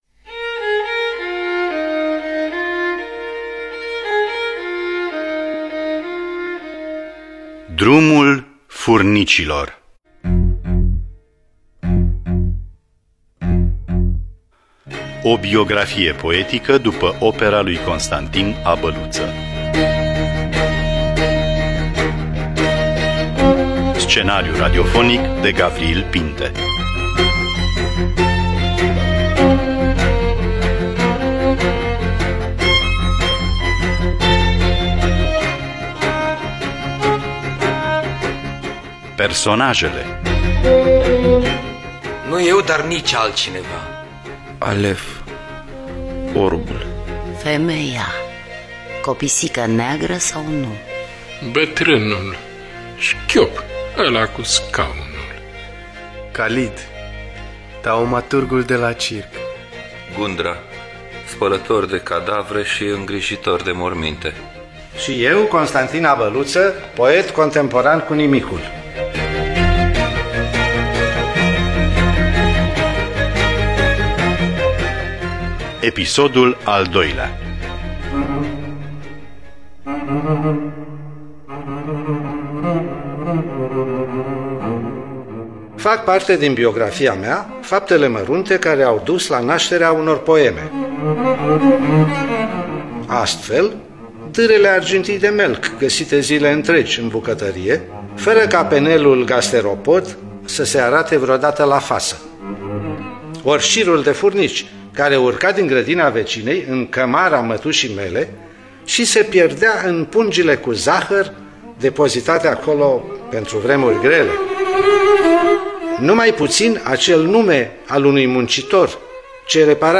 Cu participarea poetului Constantin Abăluță.